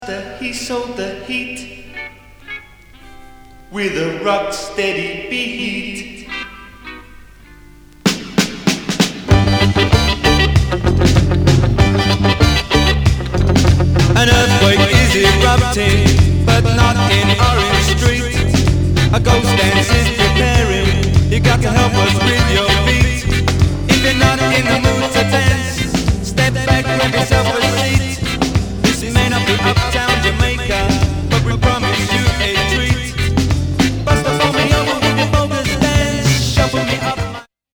スペシャルズ、セレクターと並ぶ2ト−ン代表、